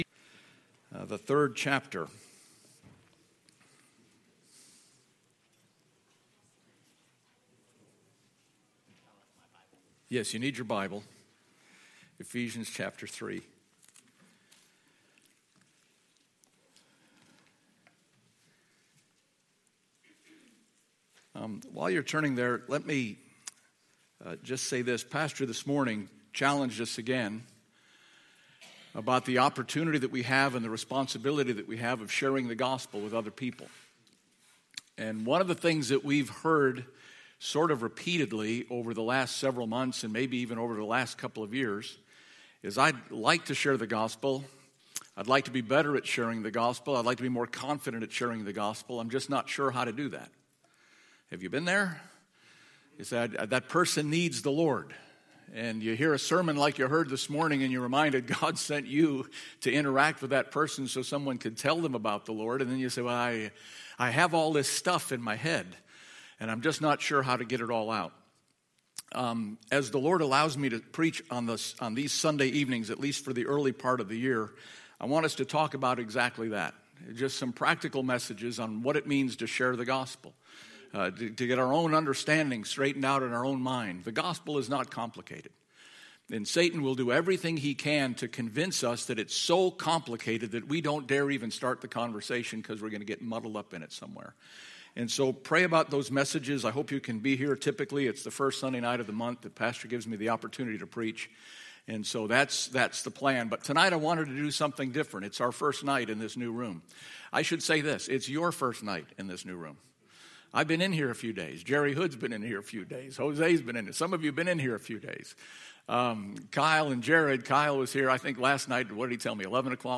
Other Sermons x x Who Is This?